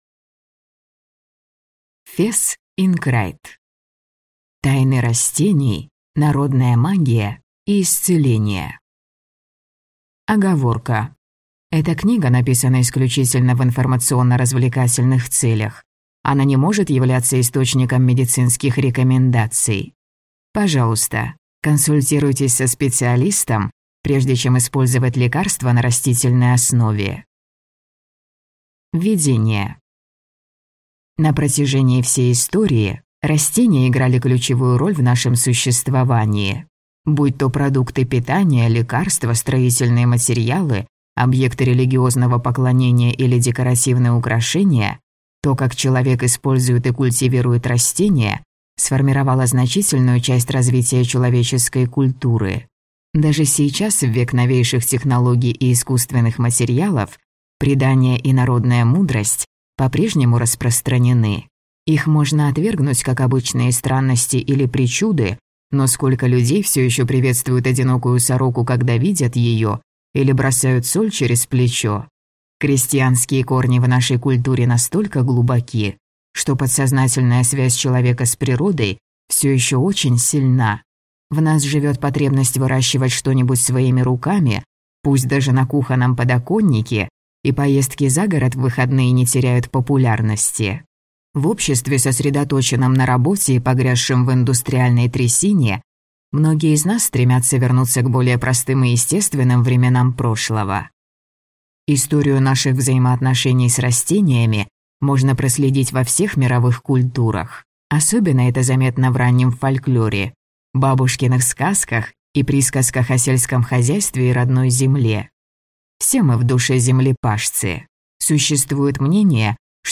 Аудиокнига Тайны растений. Народная магия и исцеление | Библиотека аудиокниг